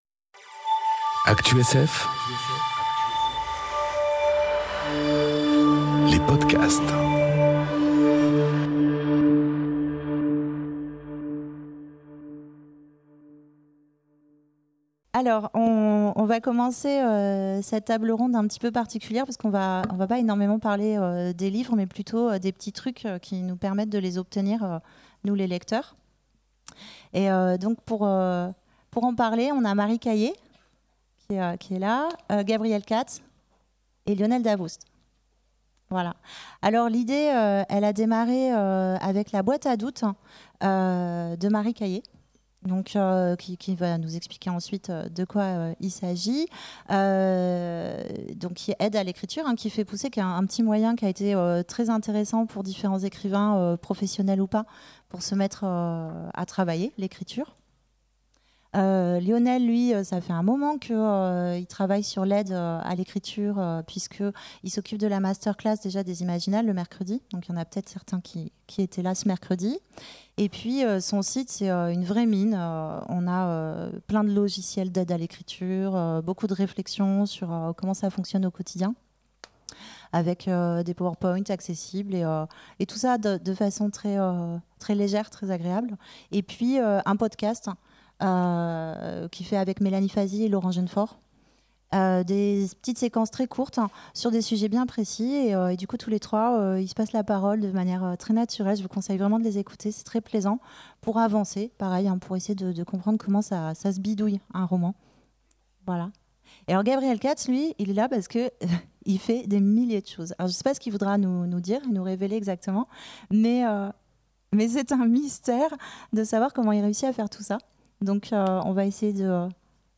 Conférence Boîte à doutes, gueuloir ou... Relaxation tantrique enregistrée aux Imaginales 2018